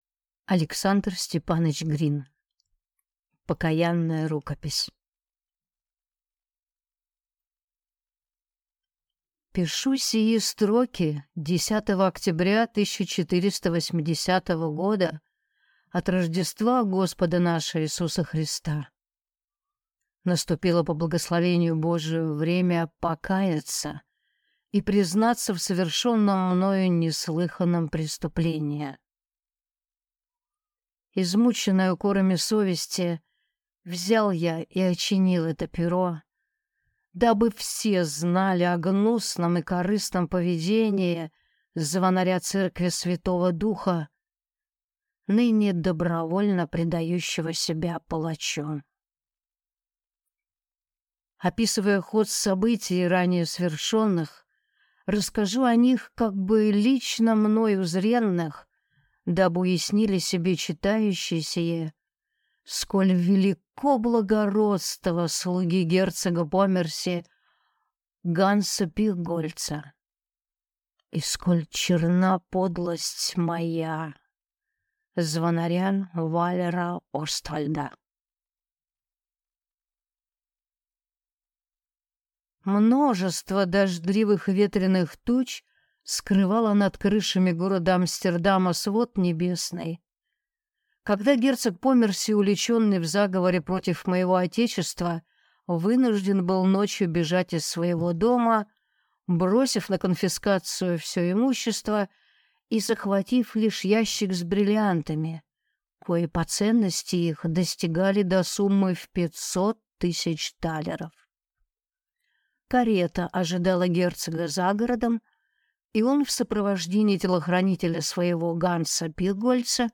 Аудиокнига Покаянная рукопись | Библиотека аудиокниг